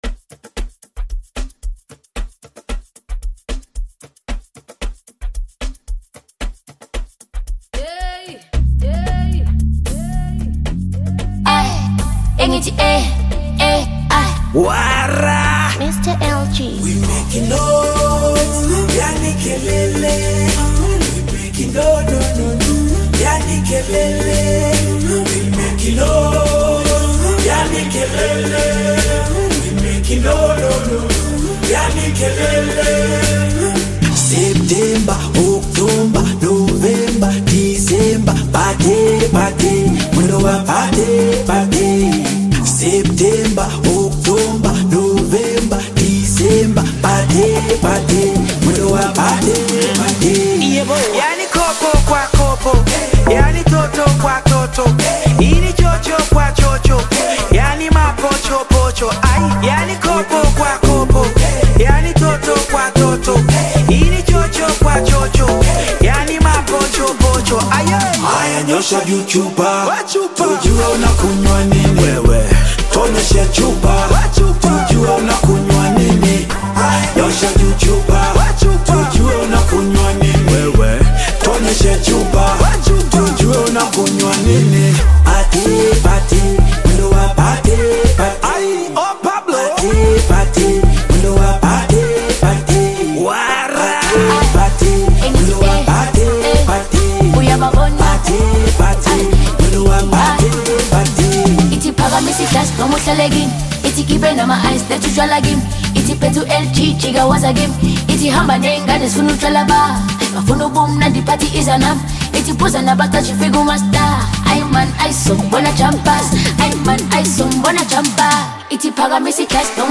Bongo Flava music track
Tanzanian Bongo Flava